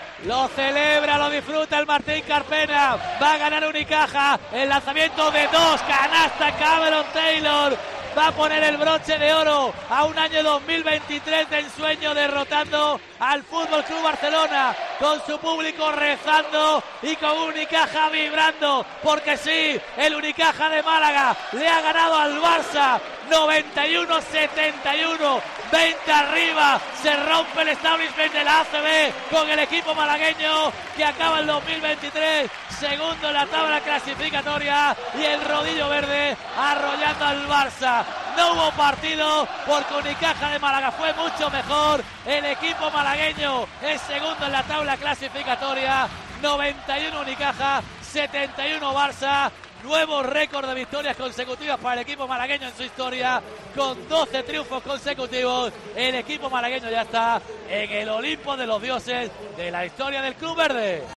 Así te narramos los últimos instantes de la gran victoria de Unicaja ante el Barça (91-71)